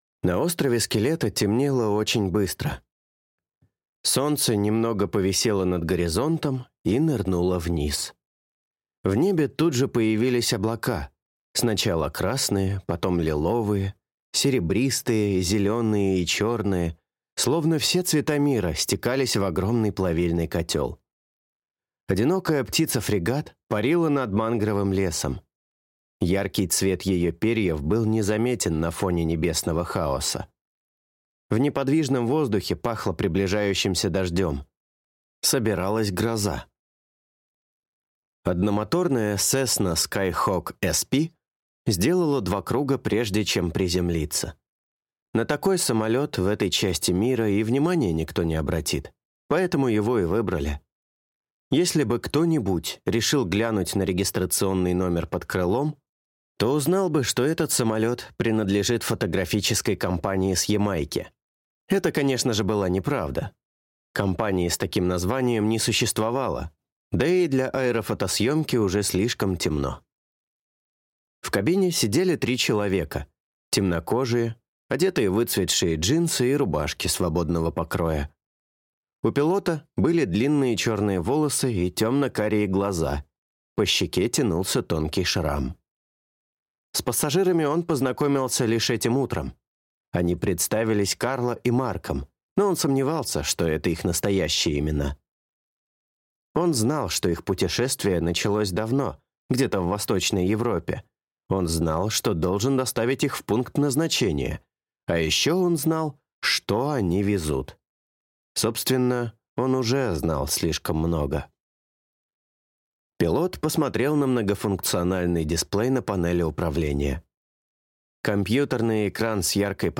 Аудиокнига Остров Скелета | Библиотека аудиокниг